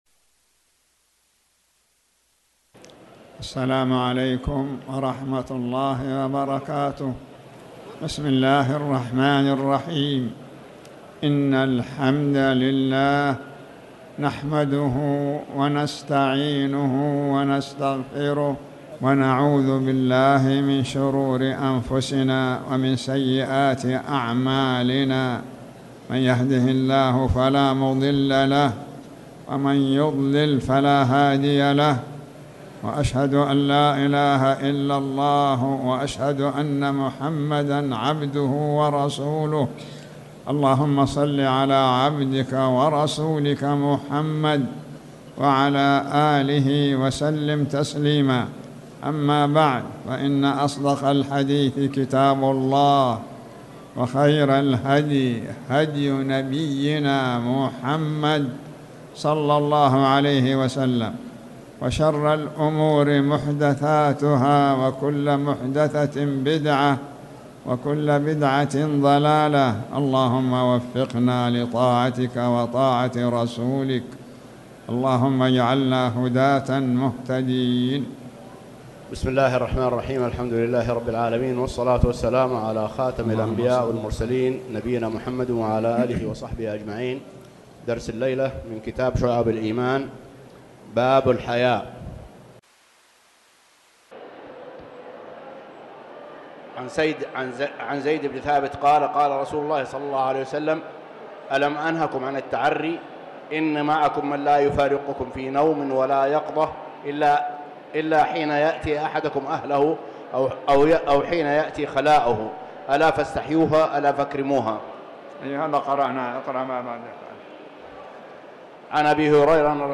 تاريخ النشر ٢٩ ربيع الأول ١٤٣٩ هـ المكان: المسجد الحرام الشيخ